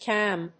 /kɑmb(米国英語)/